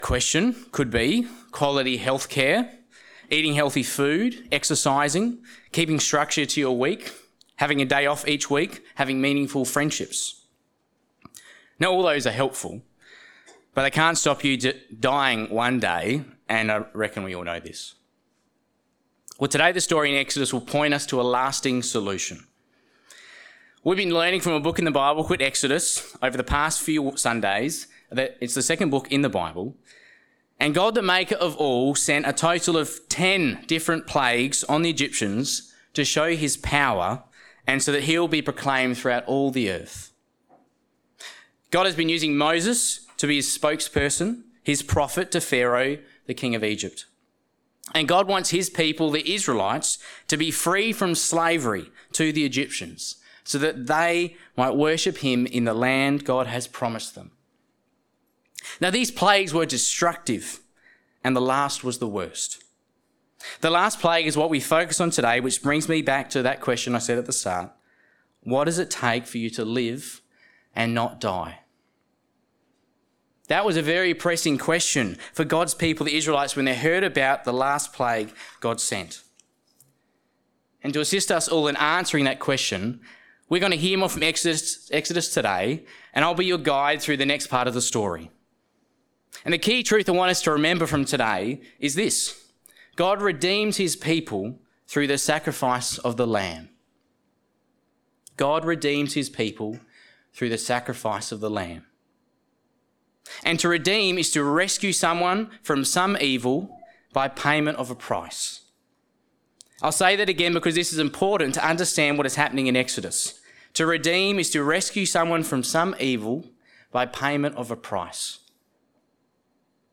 Sermon Series - St. James Anglican Church Kununurra